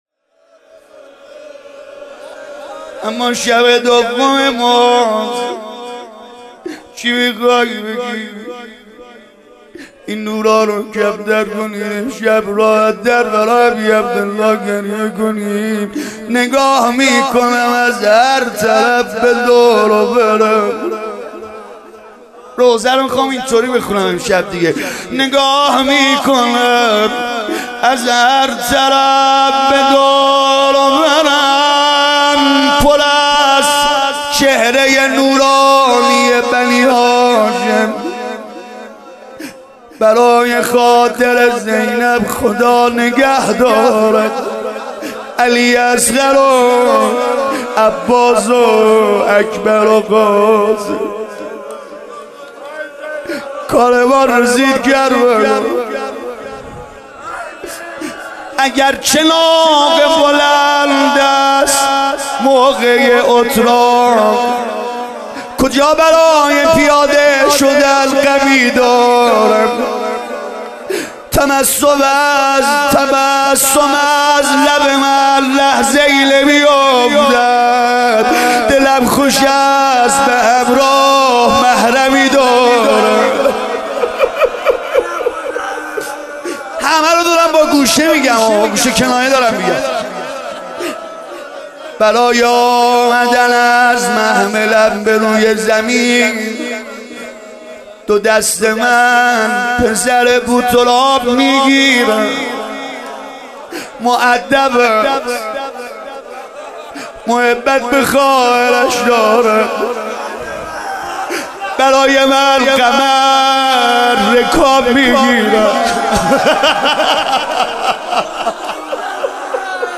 مراسم شب دوم محرم ۹۵/هیئت فداییان حسین(ع) اصفهان/مسجدمحمدیه